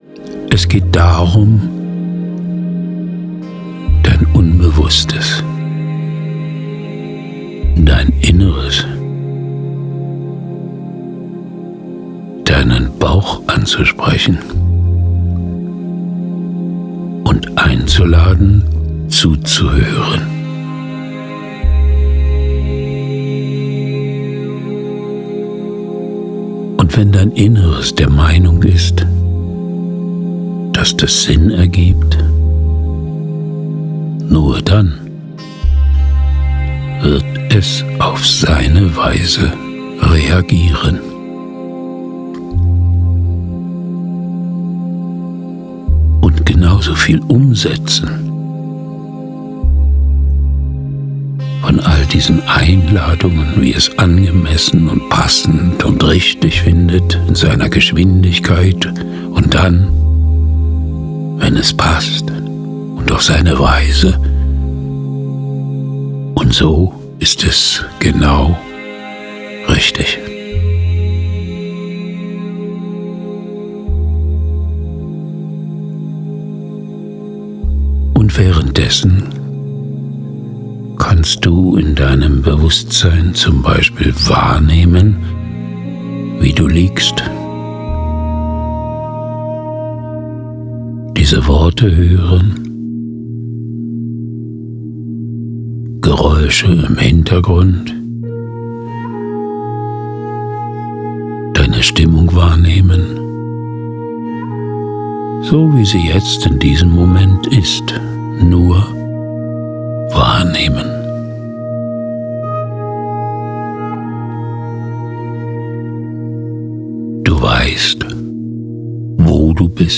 Hypno-Reise zur Achtsamkeit und Lebendigkeit
Sanft und behutsam wird man durch indirekte Suggestionen zu einem achtsamen, körperlichen Wahrnehmen geführt.
Bei diesem Hörbuch handelt es sich um einen digitalen Download.